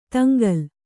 ♪ taŋgal